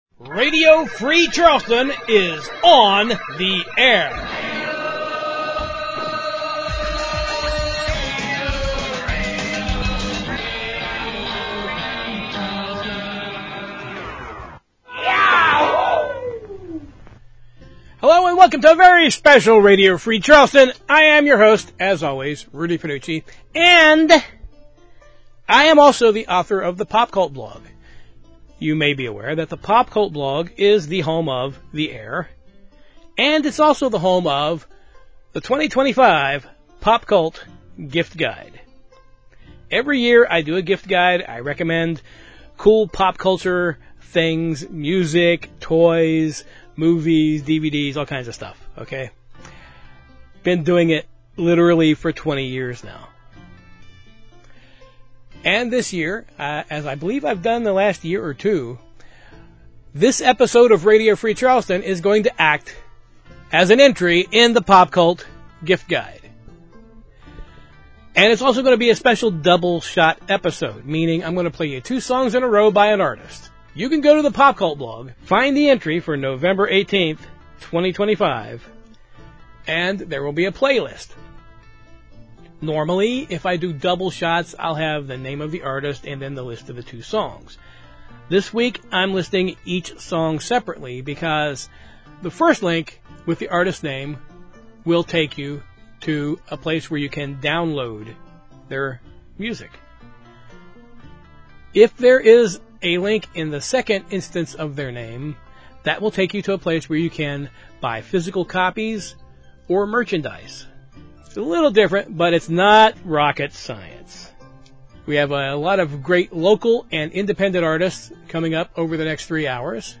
This year we are doing double-shots…two songs in a row by some of RFC ‘s favorite local and independent artists who have released new music this year.